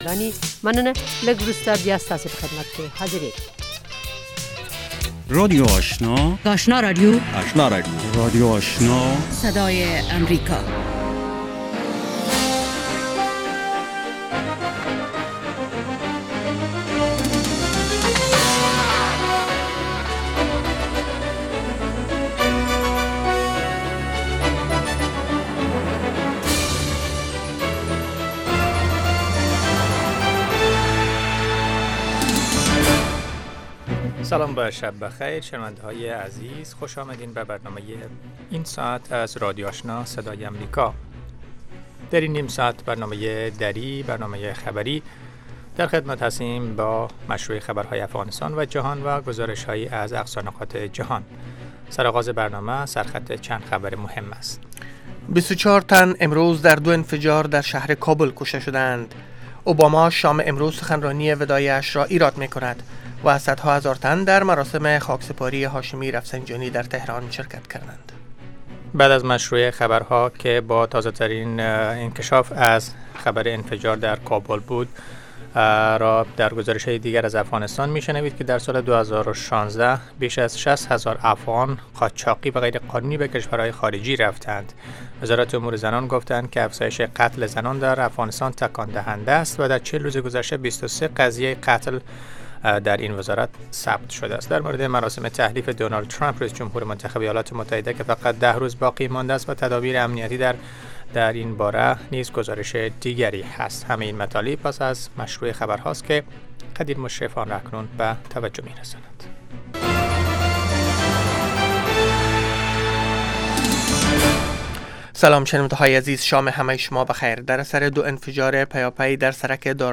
در نخستین برنامه خبری شب خبرهای تازه و گزارش های دقیق از سرتاسر افغانستان، منطقه و جهان فقط در سی دقیقه.